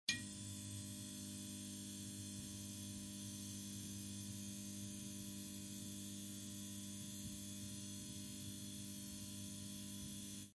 10. Шум флуоресцентного света